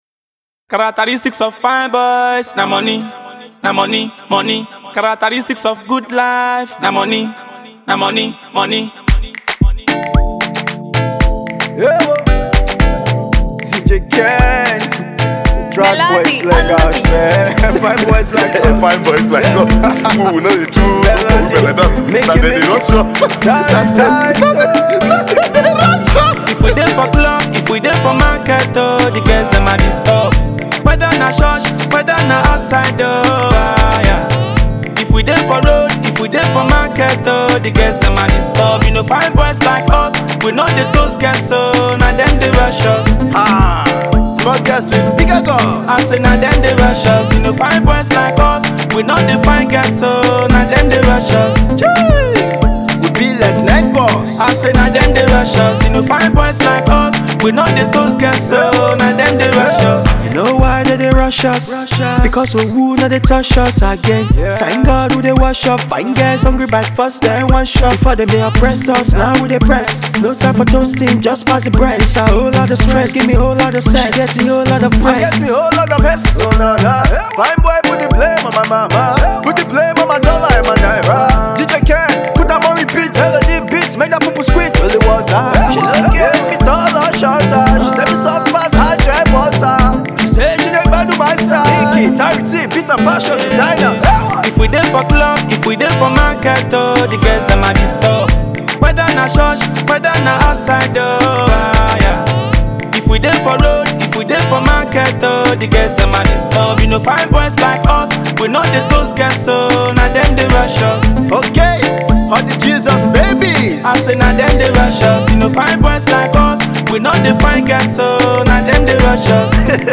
Home Afrobeat New Music
catchy brain bursting song